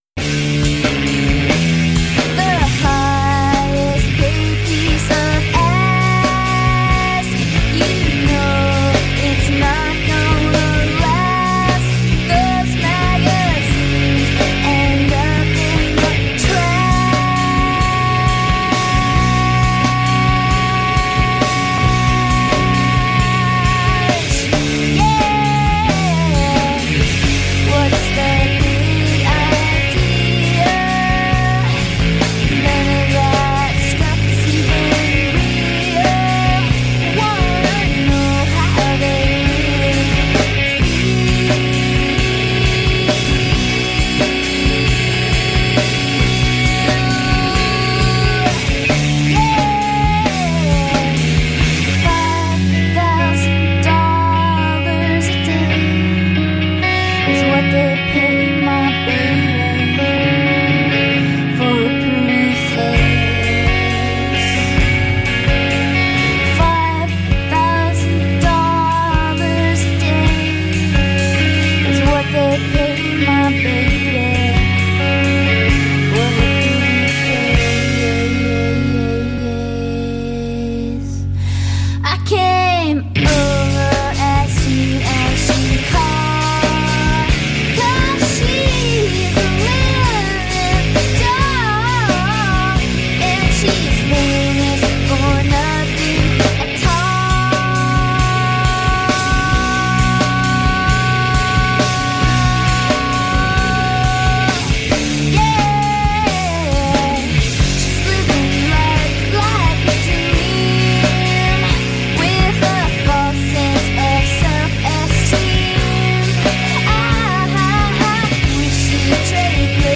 90's Americana.